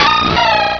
Cri d'Azumarill dans Pokémon Rubis et Saphir.
Cri_0184_RS.ogg